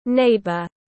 Hàng xóm tiếng anh gọi là neighbour, phiên âm tiếng anh đọc là /ˈneɪ.bər/.
Neighbour /ˈneɪ.bər/